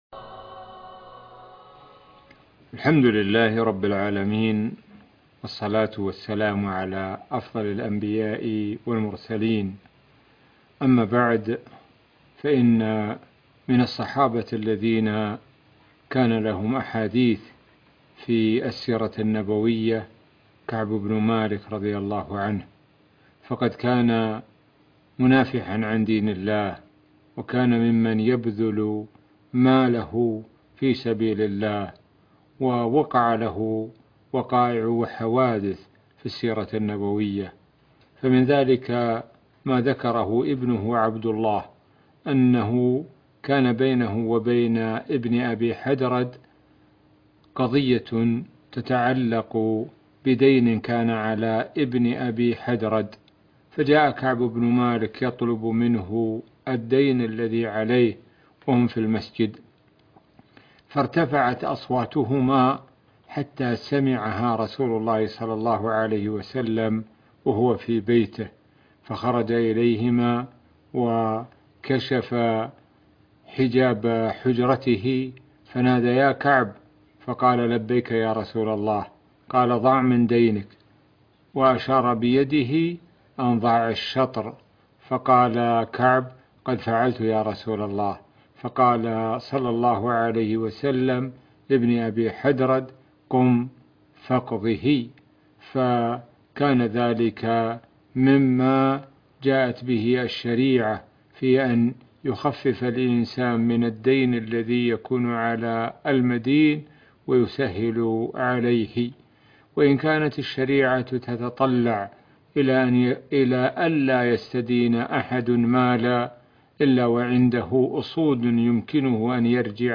الشيخ د سعد الشثري يروي قصصًا عن الصحابي الجليل كعب بن مالك رضي الله عنه - الشيخ سعد بن ناصر الشثري